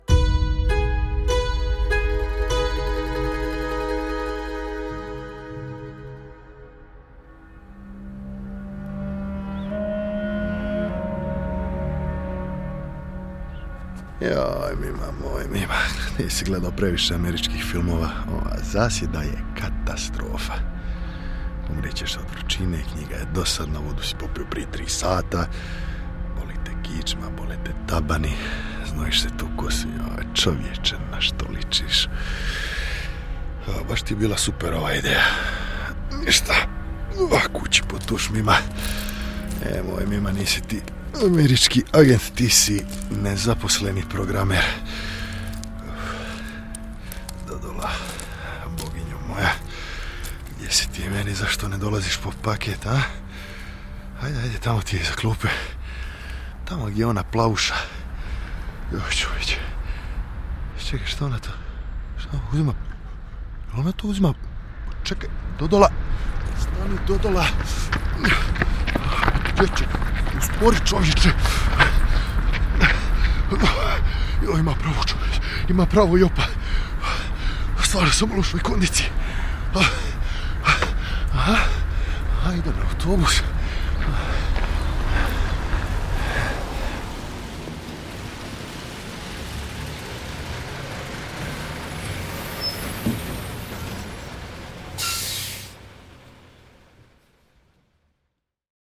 Hrvatski radio HR1 - radijski krimić